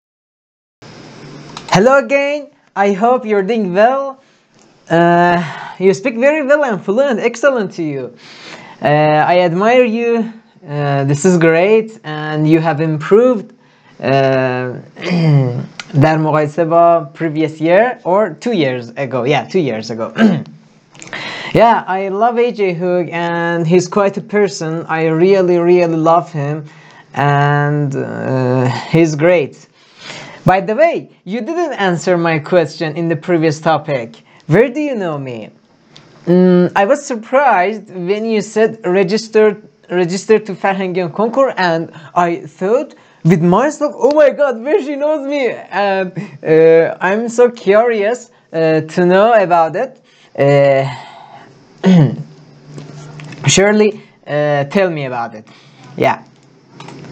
According to your level, you’re talking fairly well.
و موارد دیگه‌ای که میشه از پیشرفتت مثال زد ایناس: قدرت بیان، تلفظ بهتر لغات، لهجه بهتر و تسلط و سرعت کلامیه بهتر
صدای زیبایی دارید
روان، قشنگ و خوش‌انرژی حرف میزنید:pinched_fingers:t2::sparkles: